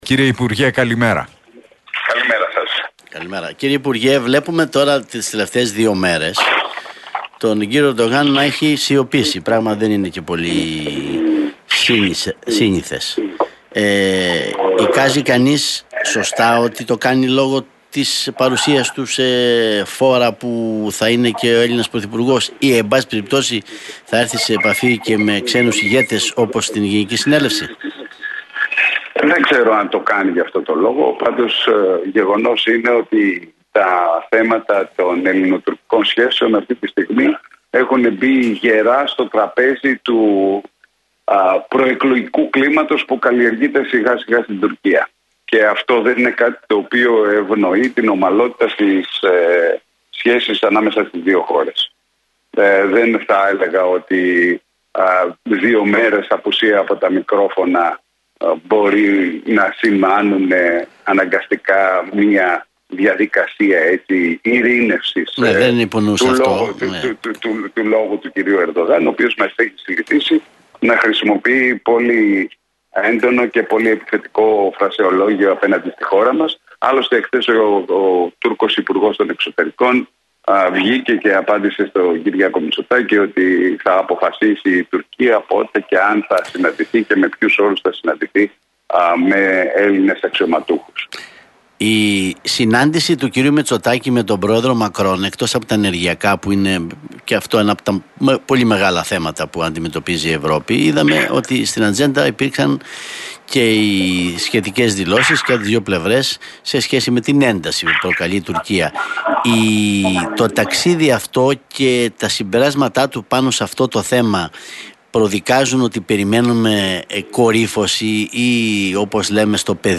Ο αναπληρωτής υπουργός Εξωτερικών Μιλτιάδης Βαρβιτσιώτης παραχώρησε συνέντευξη στον Realfm 97,8